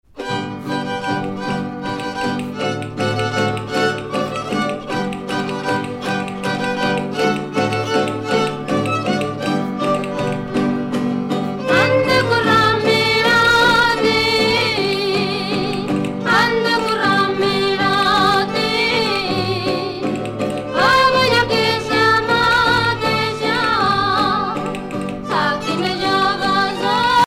danse : mateixa (Espagne)
Pièce musicale éditée